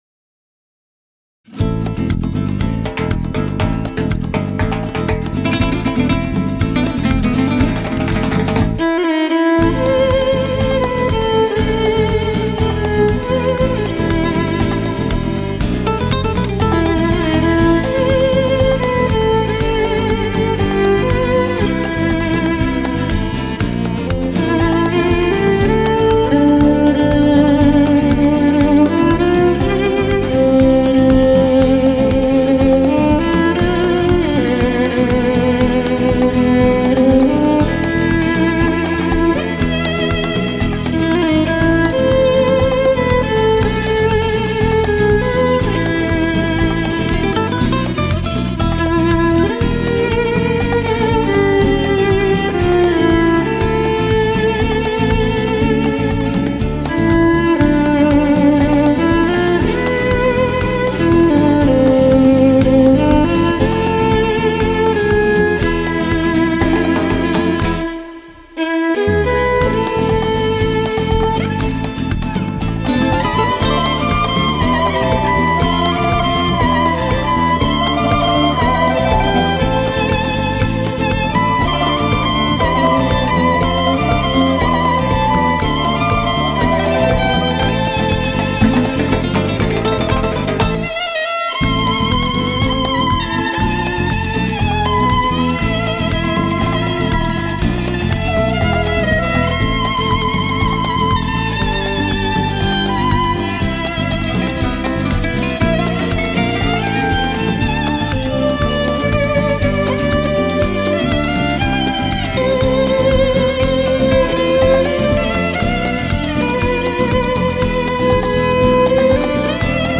Ну и, конечно, скрипка! Щиплет душу!